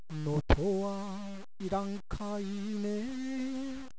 能登弁とはいえ私が能登に住んでいたころ使っていた方言です。
地域によってはまったく違った言い方の場合もあります。